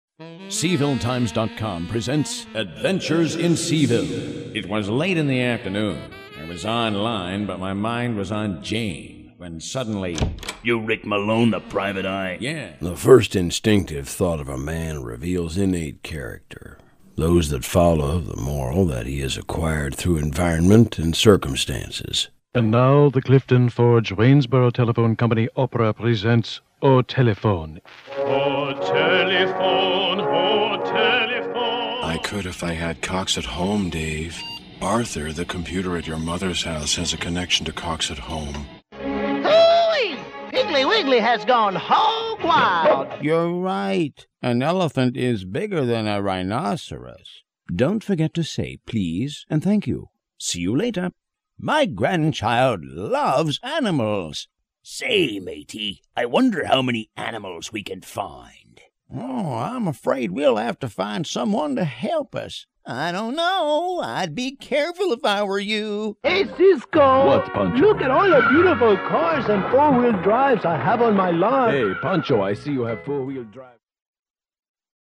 Warm, Friendly, Storyteller
Commercial Voiceover, Character